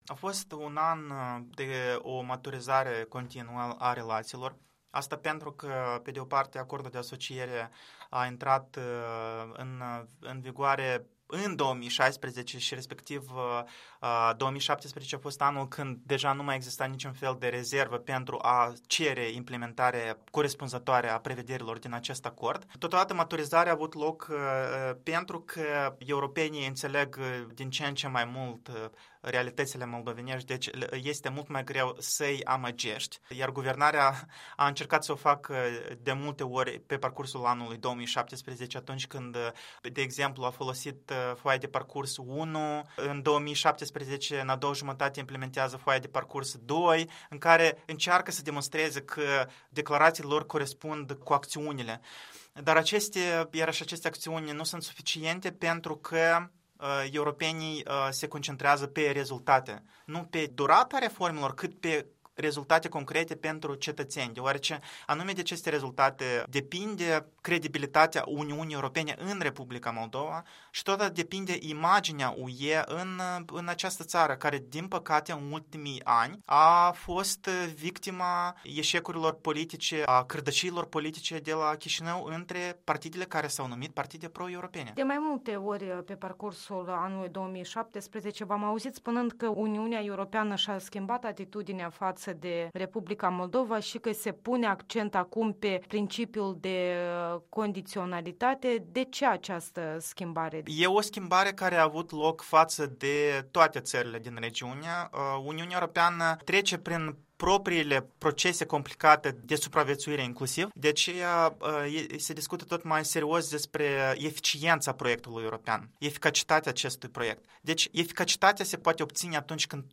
Interviul zilei cu un bilanț 2017 și prognoze ale politologului de la Chișinău.